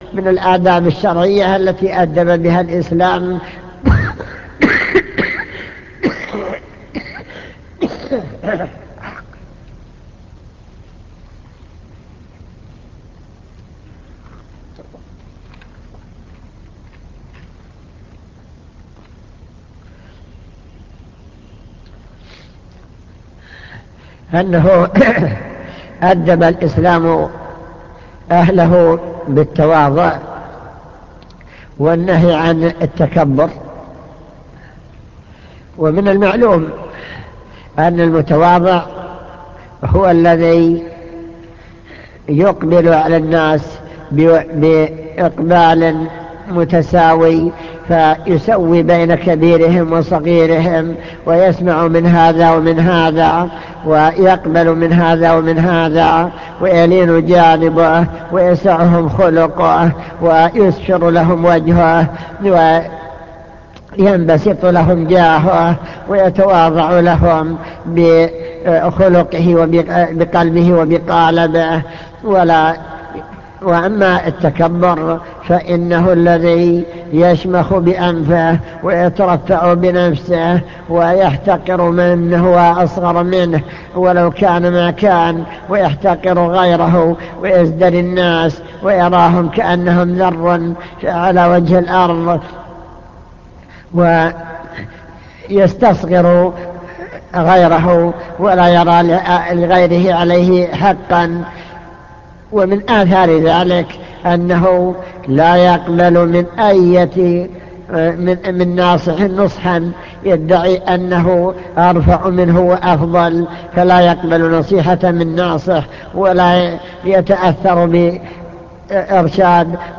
المكتبة الصوتية  تسجيلات - محاضرات ودروس  درس الآداب والأخلاق الشرعية